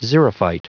Prononciation du mot xerophyte en anglais (fichier audio)